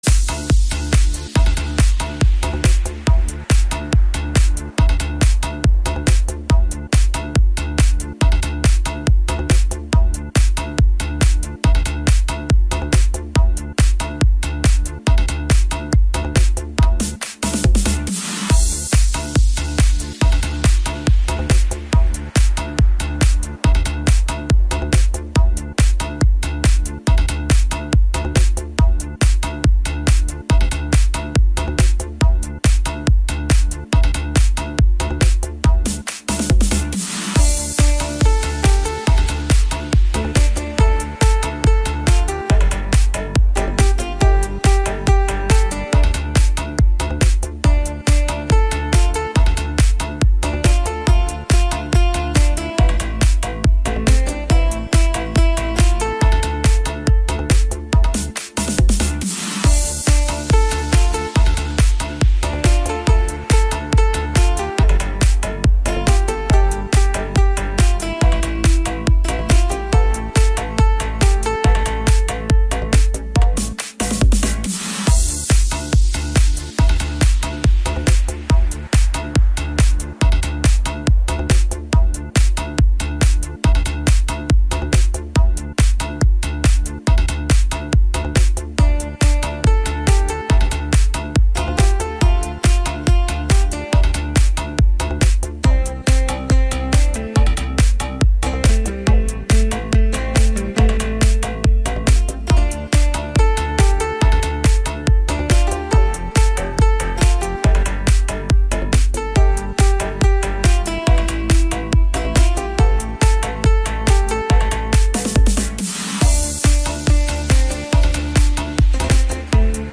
Thể loại: Nhạc nền video